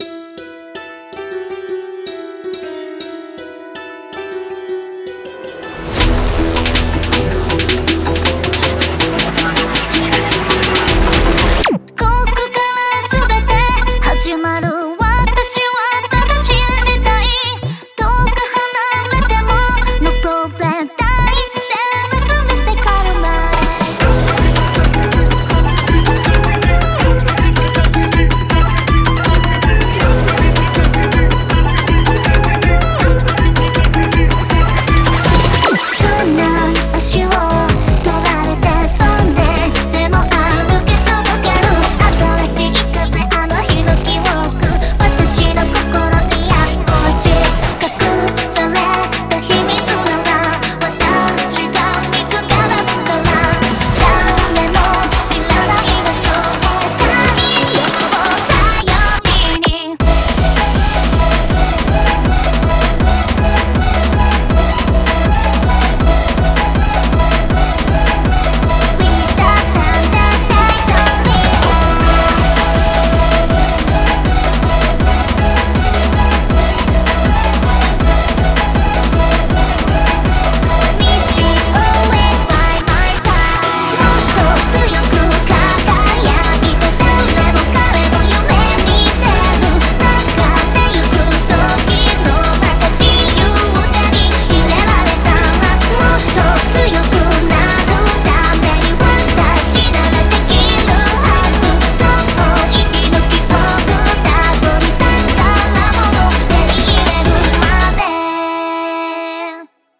BPM160
Its mix of Egyptian and bouncy music caught me right away.